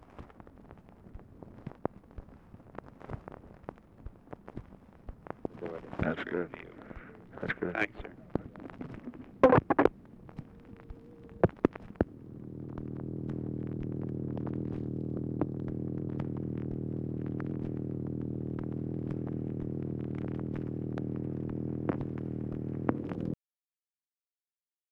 Conversation with MCGEORGE BUNDY, November 27, 1963
Secret White House Tapes | Lyndon B. Johnson Presidency